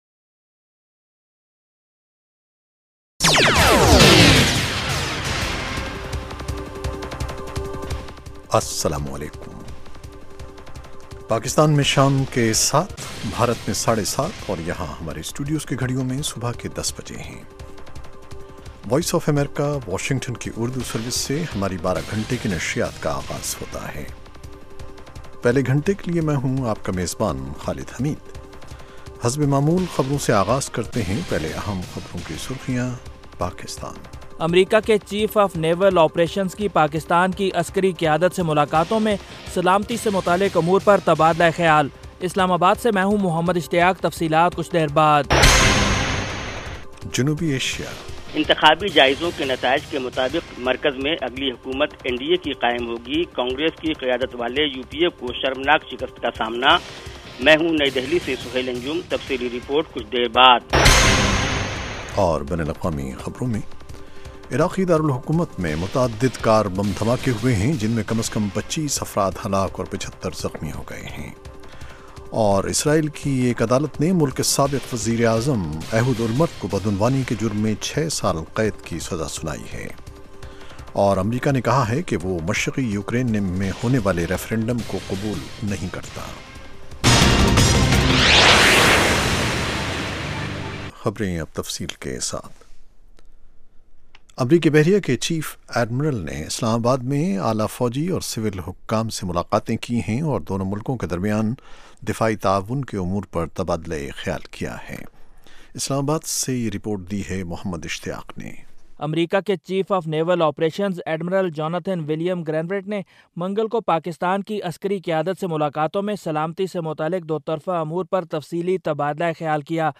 7:00PM اردو نیوز شو اس ایک گھنٹے میں دن بھر کی اہم خبریں اور پاکستان اور بھارت سے ہمارے نمائندوں کی روپورٹیں پیش کی جاتی ہیں۔ اس کے علاوہ انٹرویو، صحت، ادب و فن، کھیل، سائنس اور ٹیکنالوجی اور دوسرے موضوعات کا احاطہ کیا جاتا ہے۔